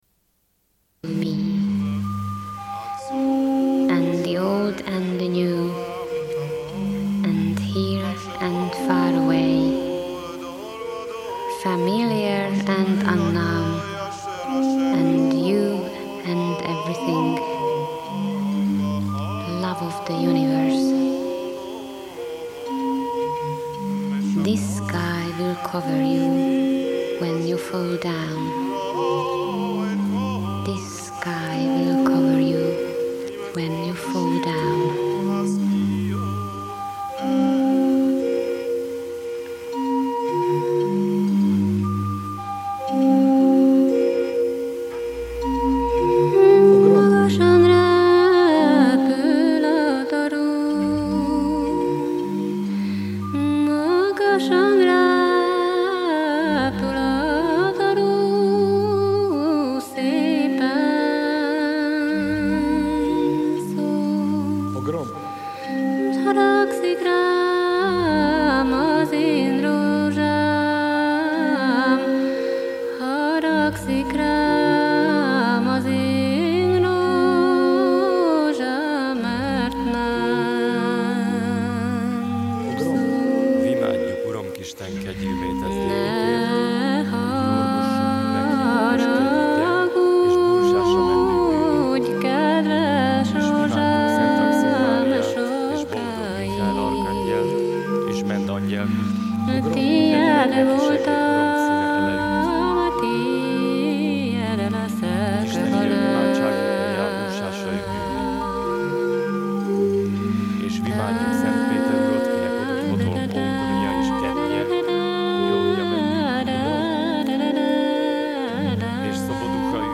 Une cassette audio, face B28:57